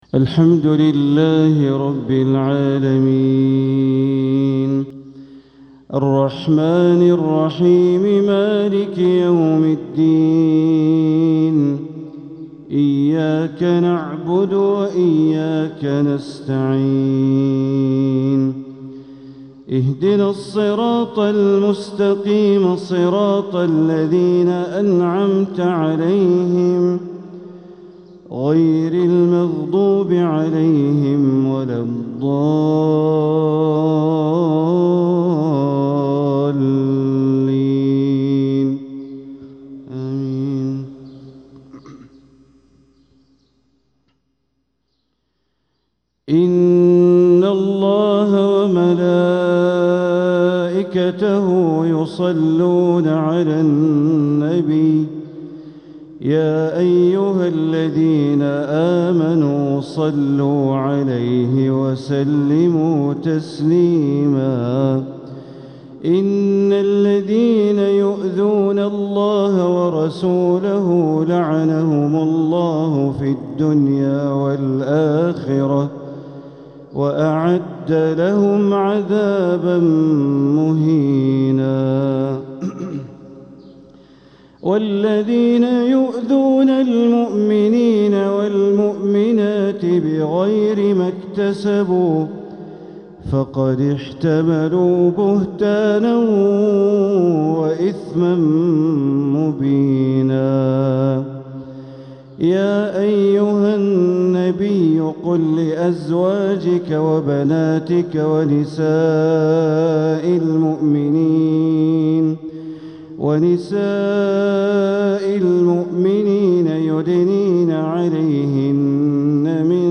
تلاوة من سورة الأحزاب | فجر الجمعة ٤ ربيع الآخر ١٤٤٧ > 1447هـ > الفروض - تلاوات بندر بليلة